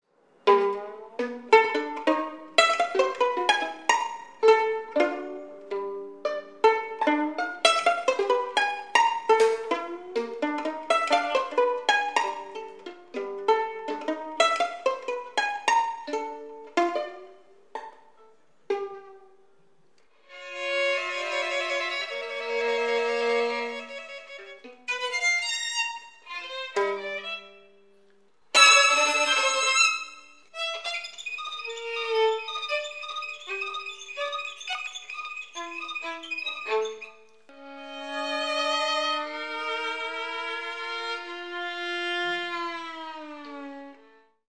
Provisonal mix-up:
violins